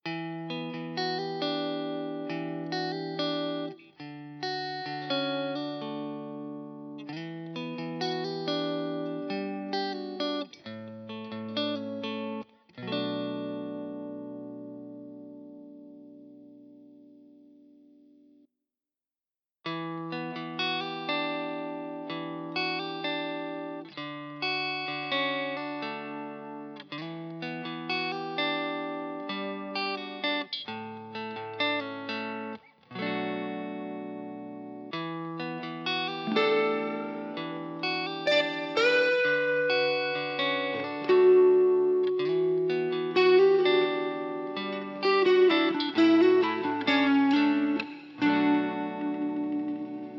Harley Benton ST-62CC MN Dakota Red Electric Guitar
По-моему неплохо звучит, играть правда трудно, но, если на доработку к мастеру отнести, думаю, нормально будет. Сперва звучат Neck+middle, потом всё - Neck Вложения harley benton vt series_NM_N.mp3 harley benton vt series_NM_N.mp3 1,9 MB · Просмотры: 2.274